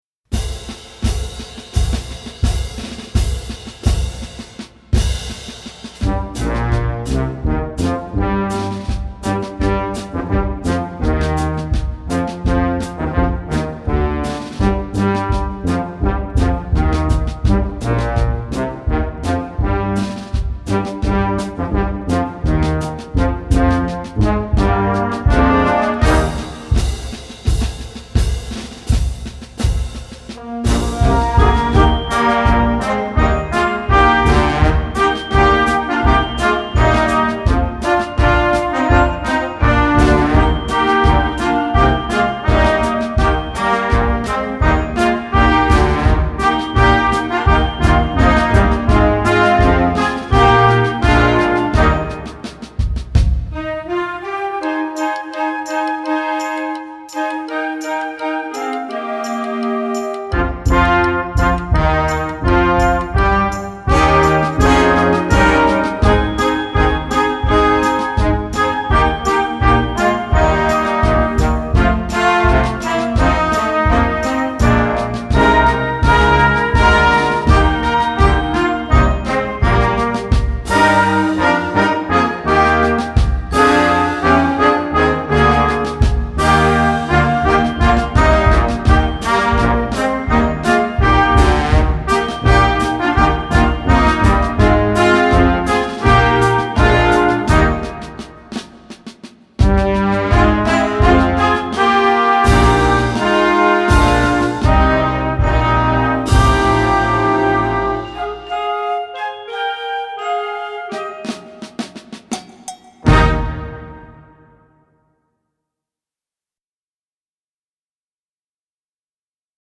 Gattung: Blasmusik für Jugendkapelle - Performer Level
Besetzung: Blasorchester